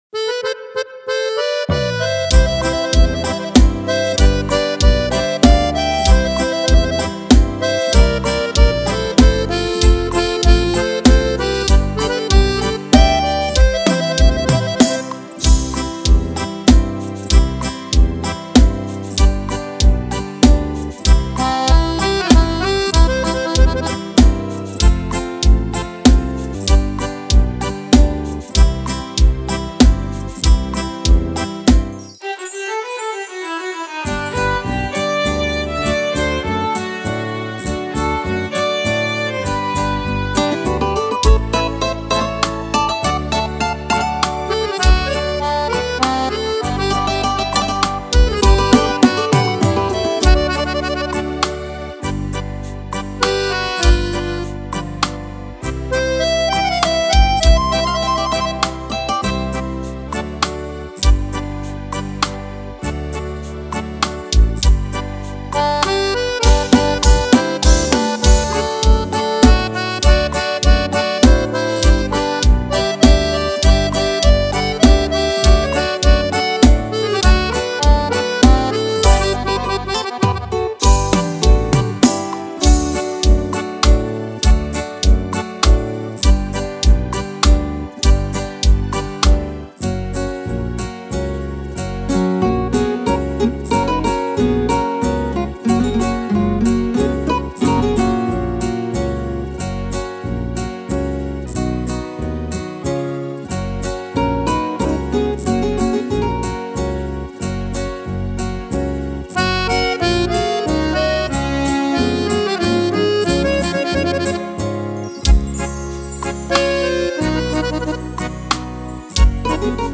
Tons Confortáveis